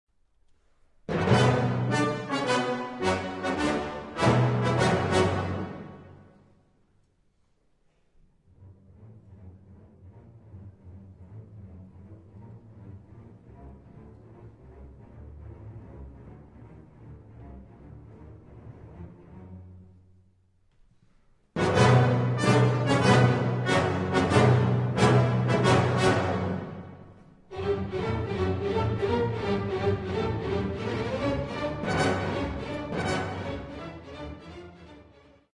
Symphony No. 11 in G minor Op. 103
Allegro non troppo 14:07